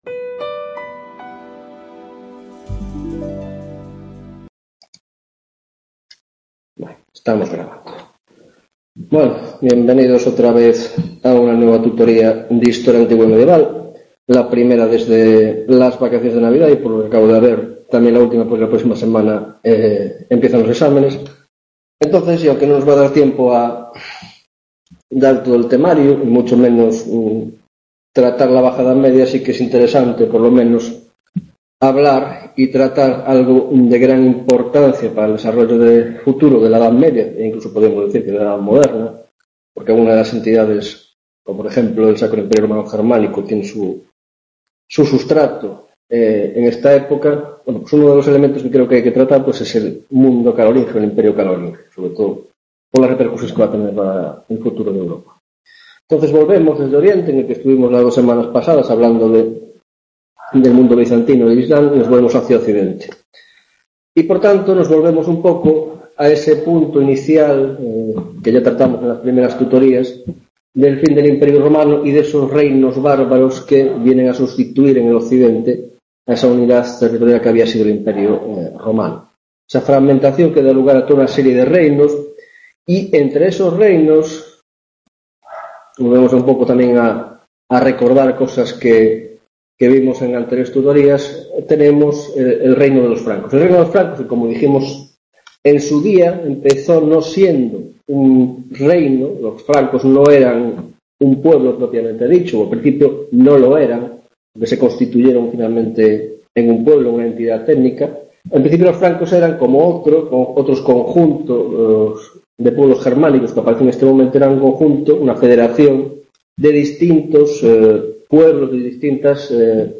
12ª Tutoria de Historia Antigua y Medieval (Grado de Filosofía): Carolingios